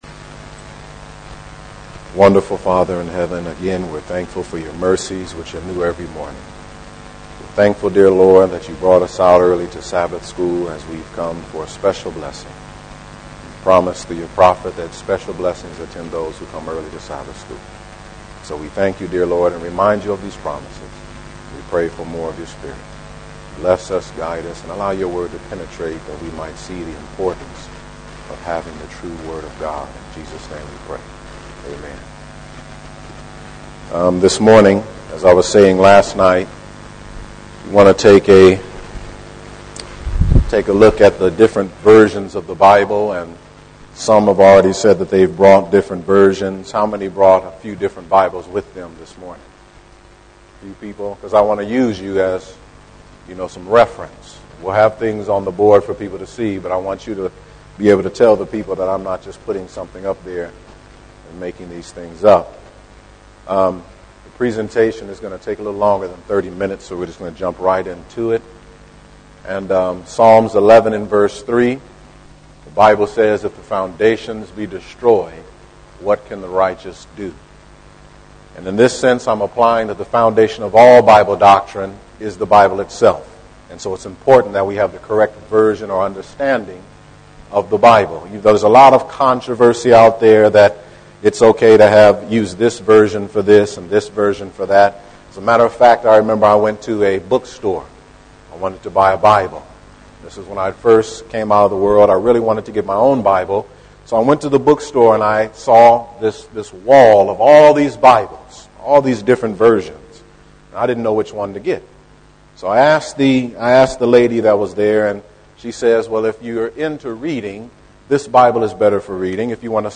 We mostly use the time honored King James Authorized Bible; explore why: "Which Bible?" Audio MP3 sermon: Information on Modern Bible Versions.